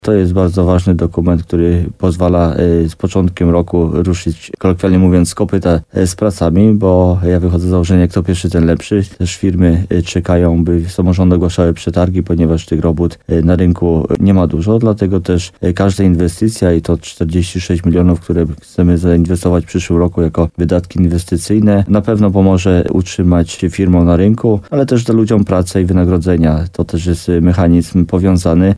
Jak powiedział w programie Słowo za Słowo w radiu RDN Nowy Sącz wójt gminy Grybów Jacek Migacz, przyszłoroczny budżet jest bardzo rozwojowy.